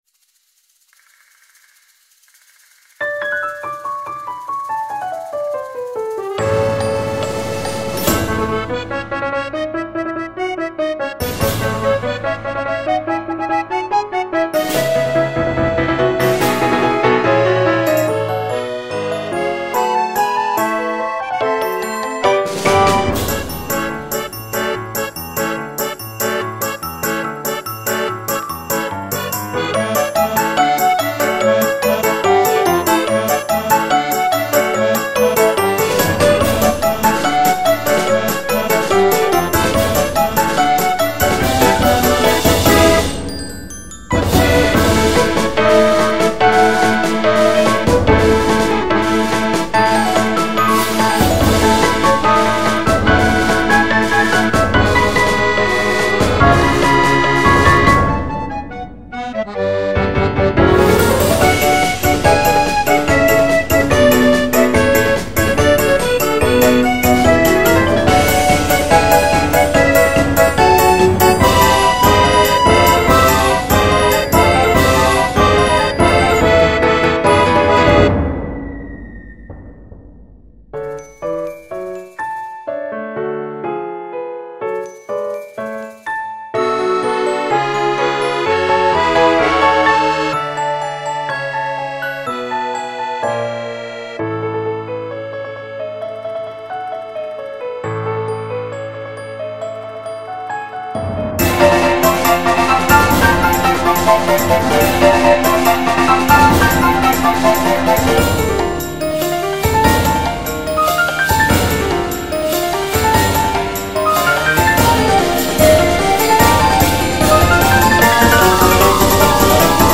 BPM90-152
Audio QualityPerfect (Low Quality)